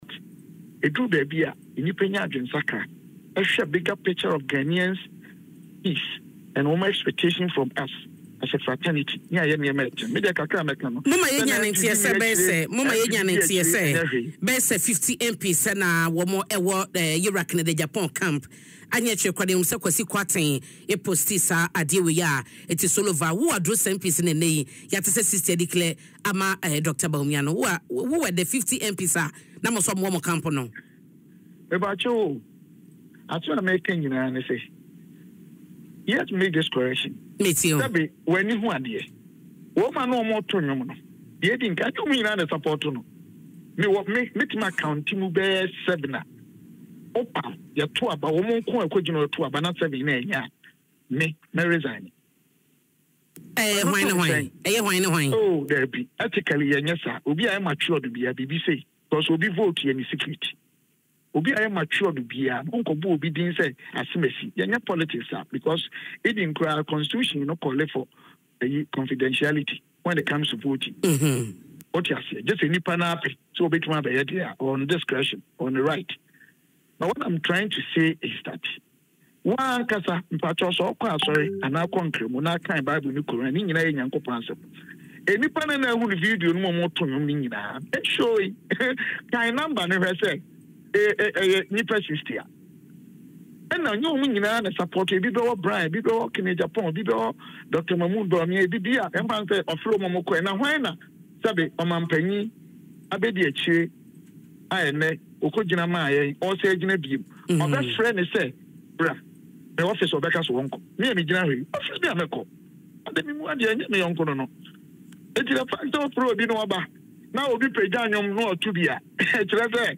Speaking on Adom FM’s Dwaso Nsem morning show, the MP insisted that some of the MPs who appeared in the group photo actually support other candidates.